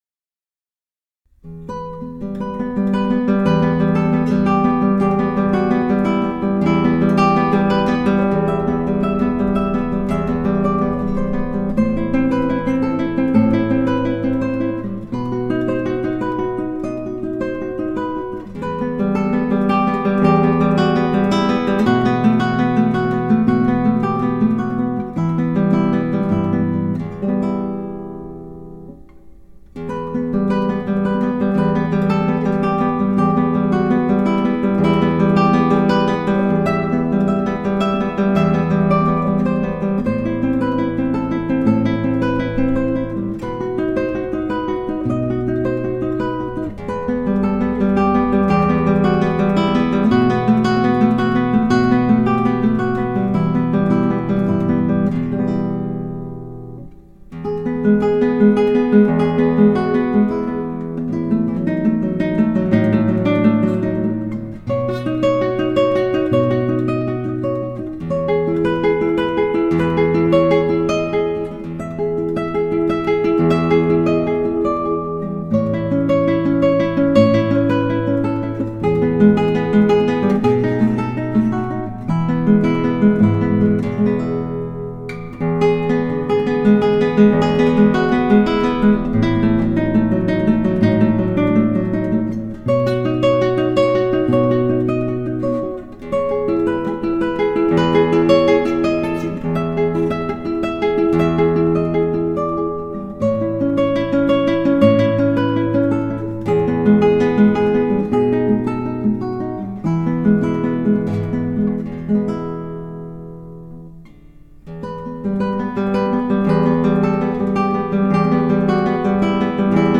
クラシックギター　ストリーミング　コンサート
「ロマンス」 　スペイン民謡
■マイク ■AT4040とNT2AをORTFセッティングで100センチの距離
■マイクプリアンプ■ARTのDPSⅡセッティングはフラット